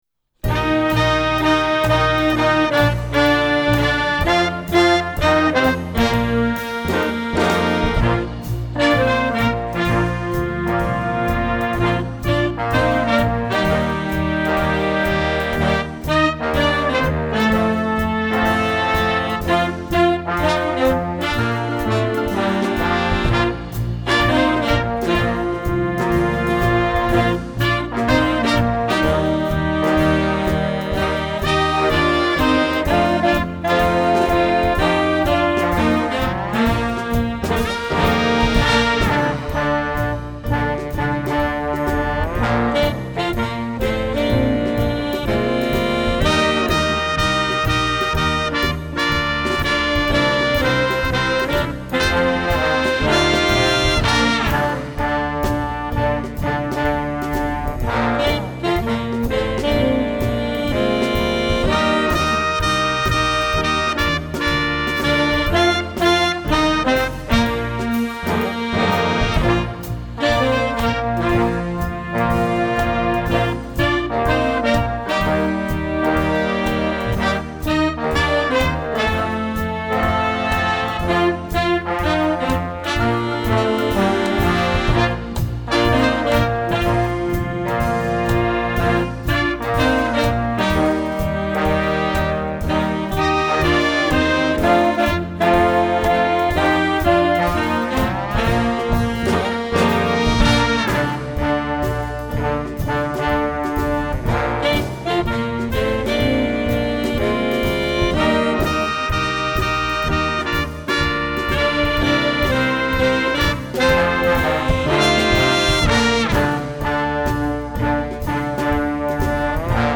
Style: Swing
Instrumentation: Standard Big Band